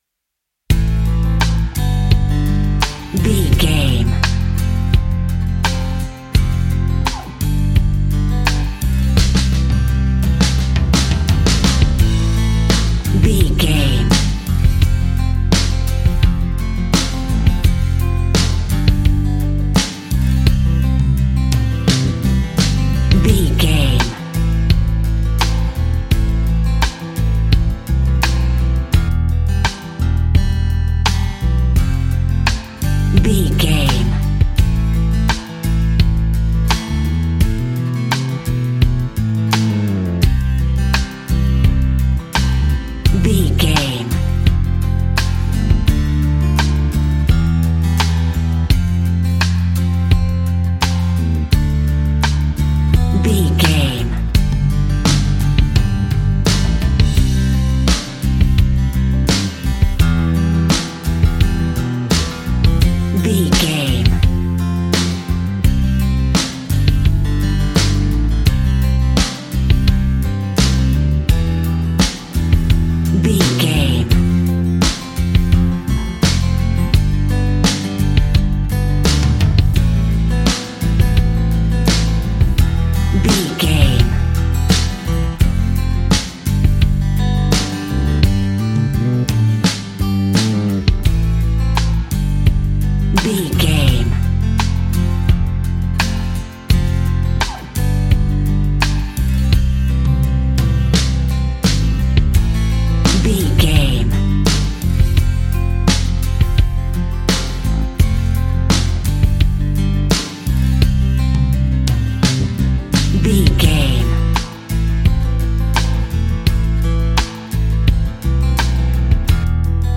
Ionian/Major
E♭
romantic
acoustic guitar
bass guitar
drums